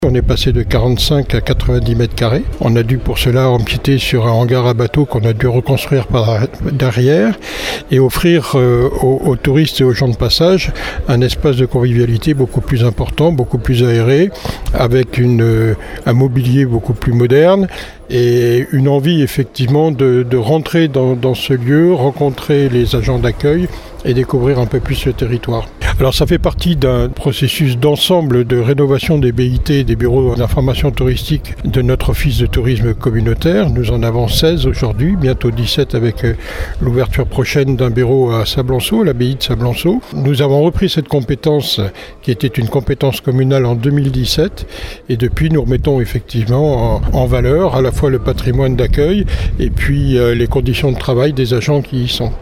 L’inauguration hier après-midi du nouveau Bureau d’information touristique de Ronce-les-Bains, à La Tremblade.
Vincent Barraud, président de la CARA et de l’Office de tourisme communautaire :